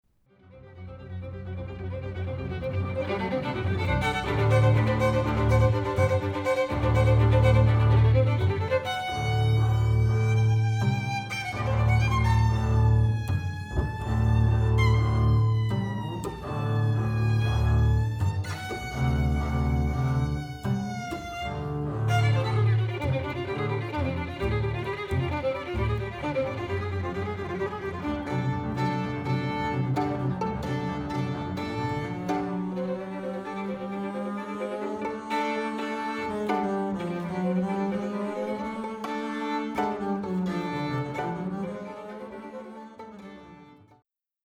violin & contrabass (or violin & violoncello)